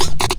10 LOOPSD1-R.wav